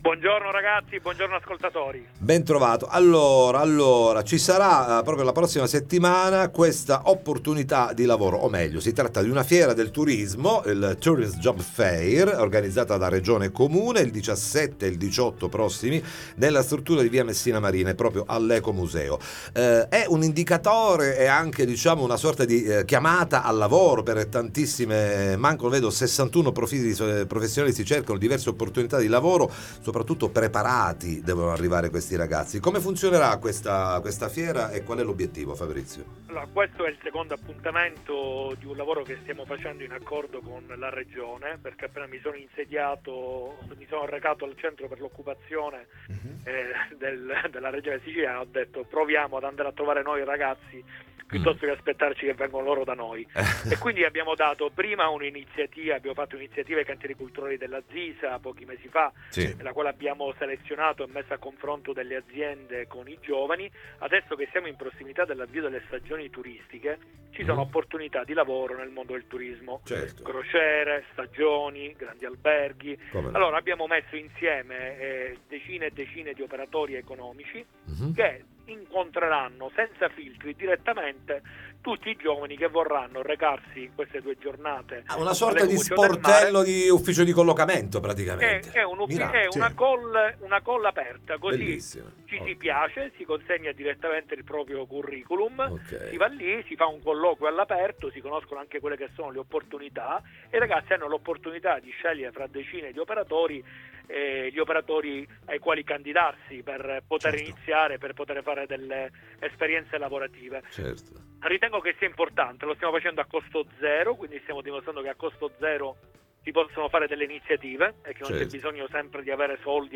All’Ecomuseo del mare una fiera del turismo e opportunità di lavoro, ne parliamo con Fabrizio Ferrandelli